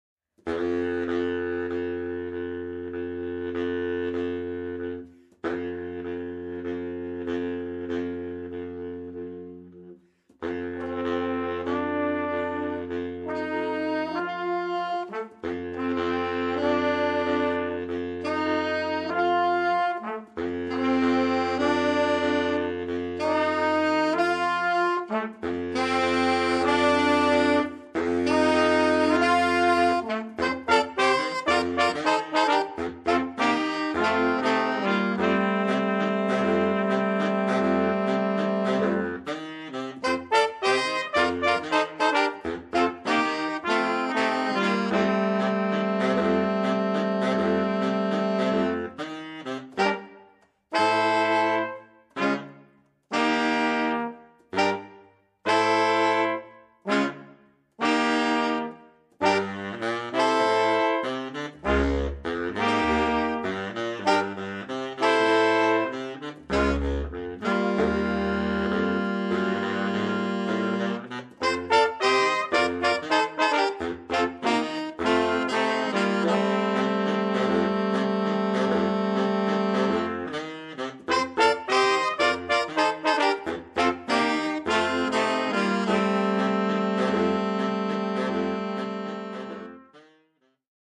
Bl�serensemble mit frisch-jazzigem Sound.